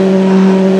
Impreza08rally.wav